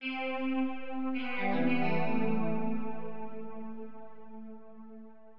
fx 8 (sci-fi)